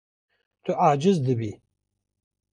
Pronounced as (IPA) /ɑːˈd͡ʒɪz/